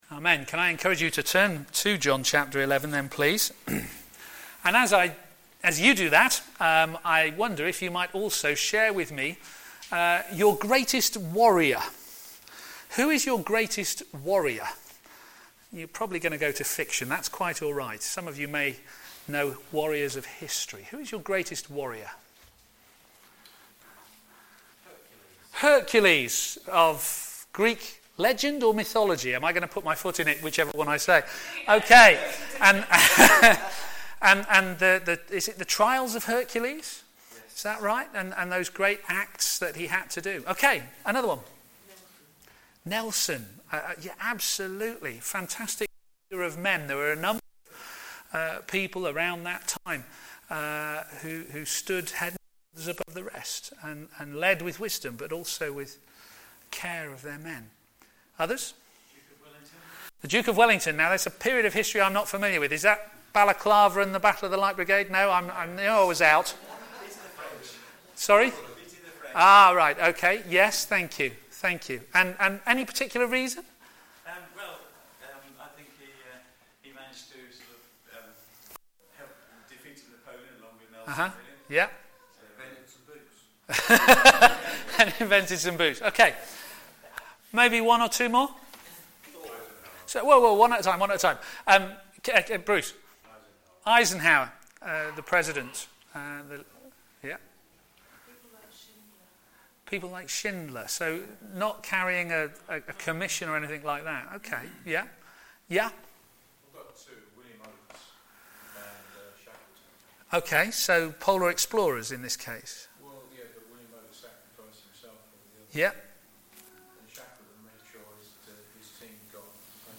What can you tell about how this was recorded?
Media for a.m. Service on Sun 24th Aug 2014 10:30